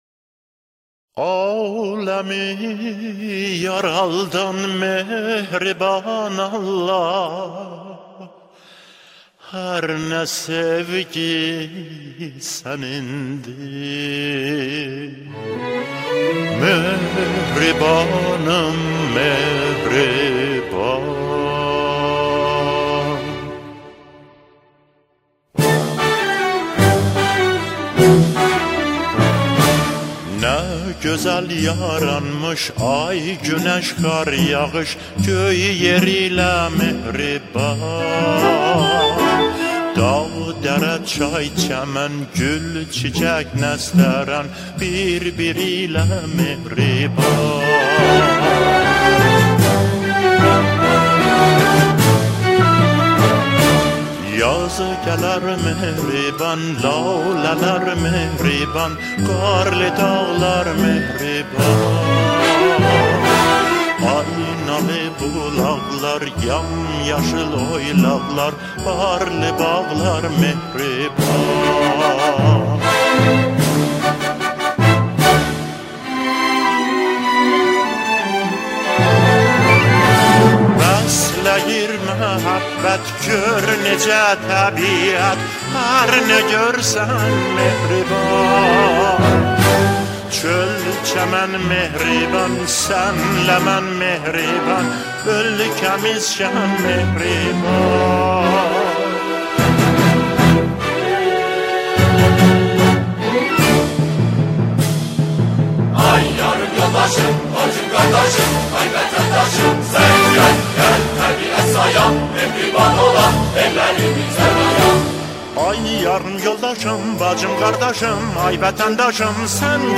همخوانی
شعر این اثر به گویش آذری سروده شده است.